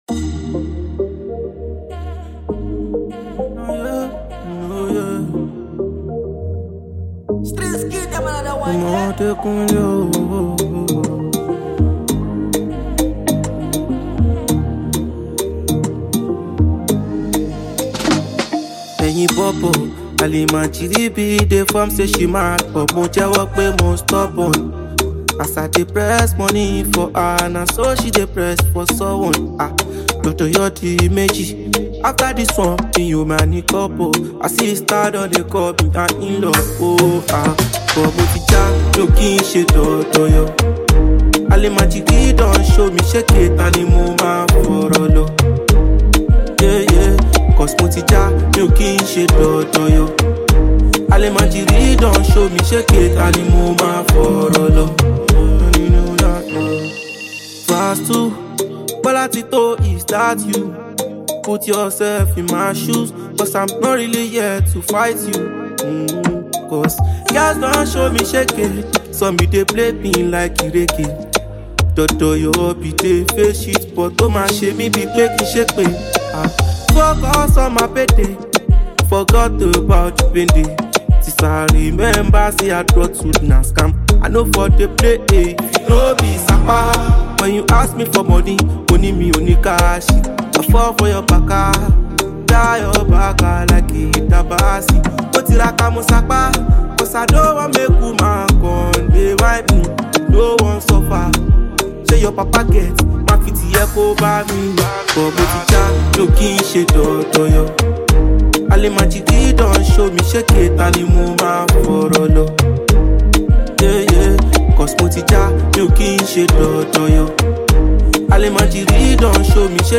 The beat is tight, the words are clear